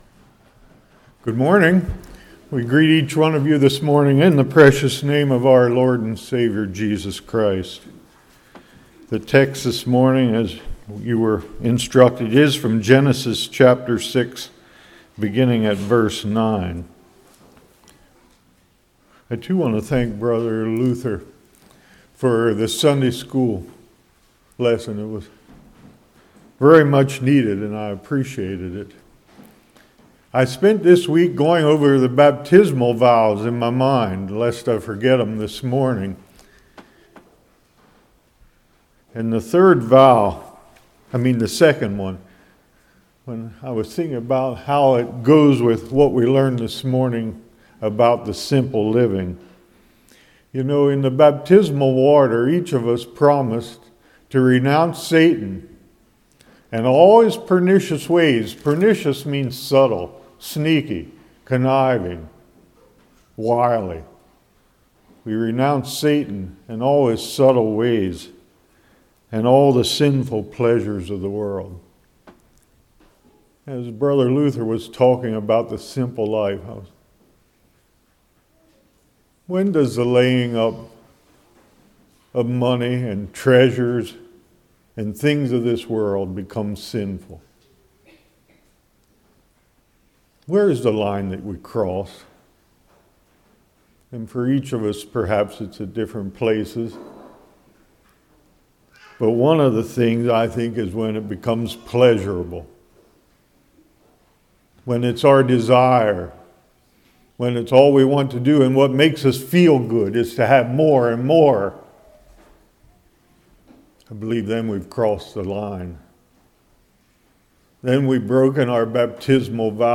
Genesis 6:9-22 Service Type: Morning Obedience of Noah Questions